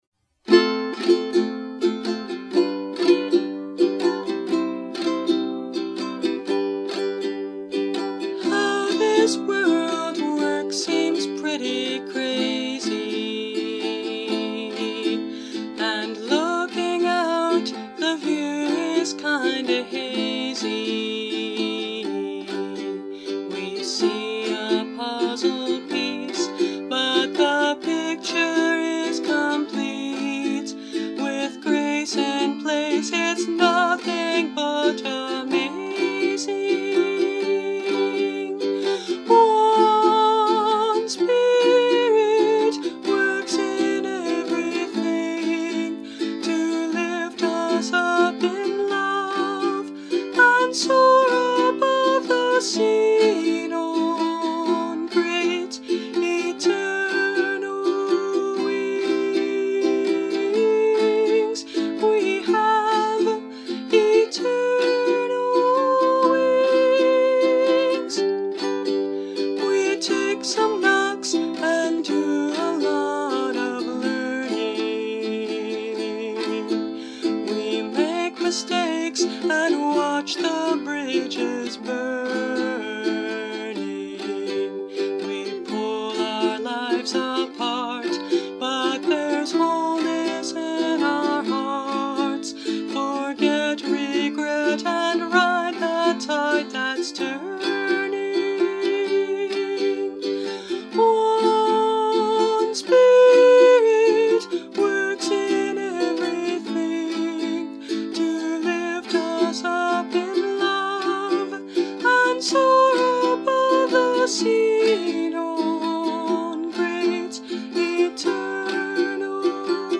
Instrument: Brio – Red Cedar Concert Ukulele